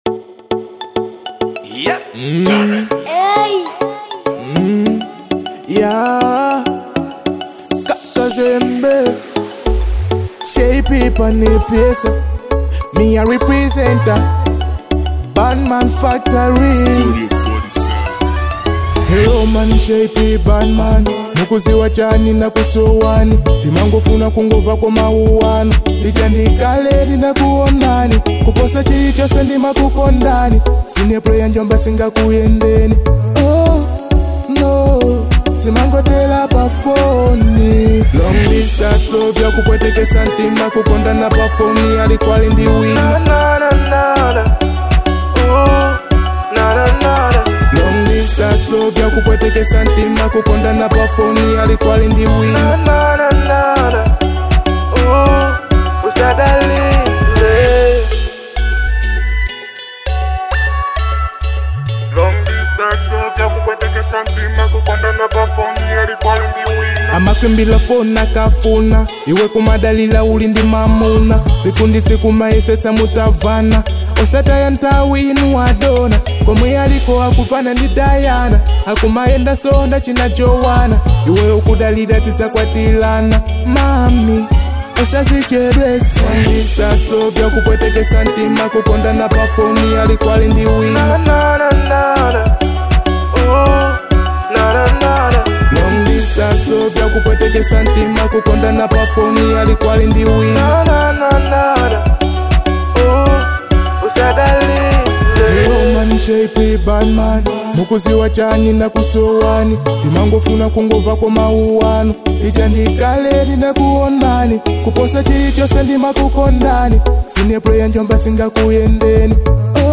Dancehall